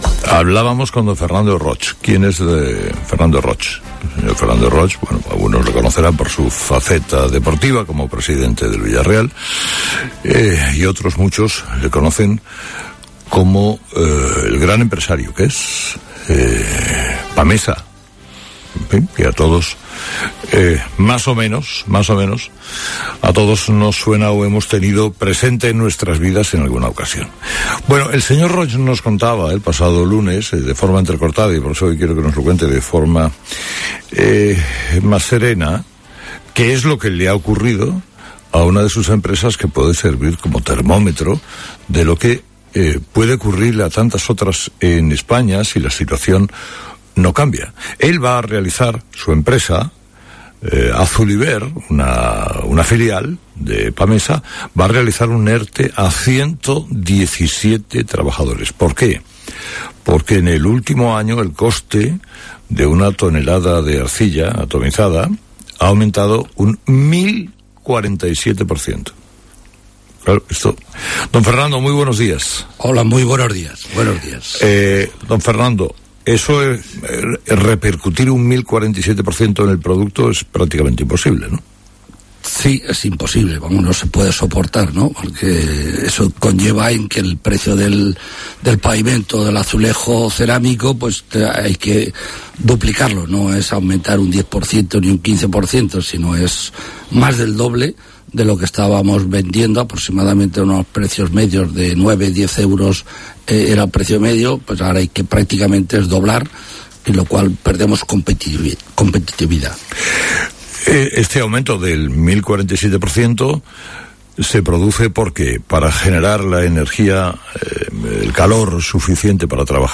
El presidente del Grupo Pamesa, Fernando Roig, ha pasado por "Herrera en COPE" para analizar el cierre de la planta de Azuliber por el sobrecoste del gas.